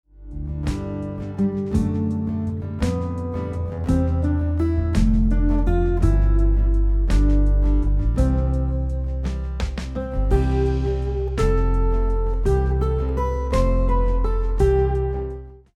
guitar arrangement preview